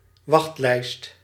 Ääntäminen
Tuntematon aksentti: IPA: /køː/